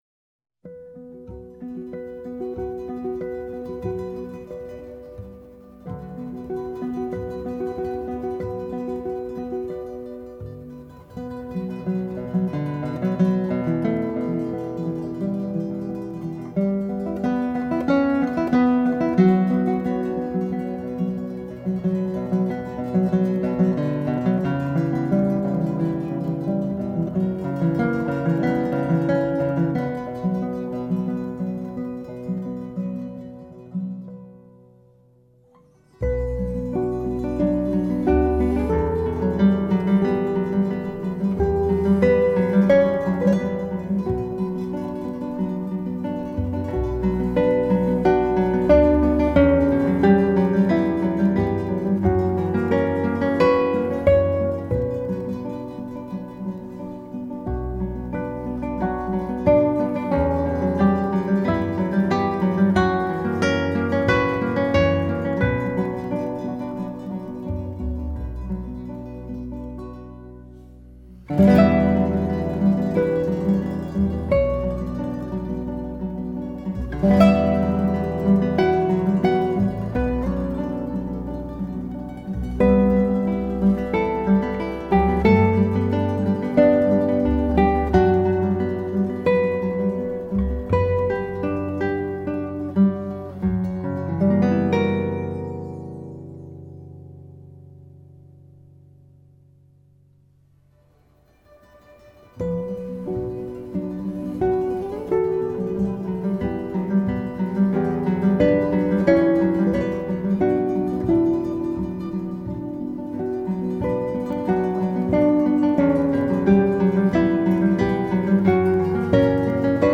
گیتار کلاسیک آرامش بخش و دلنشین